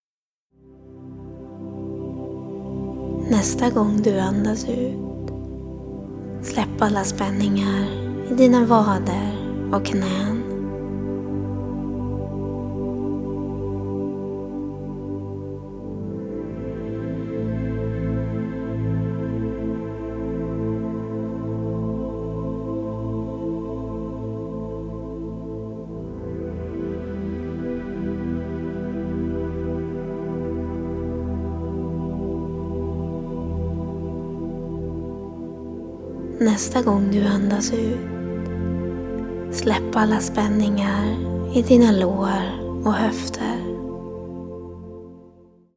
Meditation för fysisk avslappning är en vägledd meditation där du stegvis, en kroppsdel i taget, får hjälp att släppa spänningar i din kropp.
När du slutligen arbetat dig igenom hela kroppen får du vila en stund till behaglig bakgrundsmusik av den australiensiske kompositören Christopher Lloyd Clarke. Även om titeln på meditationen är Meditation för fysisk avslappning, kan den lika gärna användas för att motverka eller hantera stress.
Bakgrundsmusik: